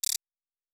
pgs/Assets/Audio/Sci-Fi Sounds/Interface/Error 09.wav at master
Error 09.wav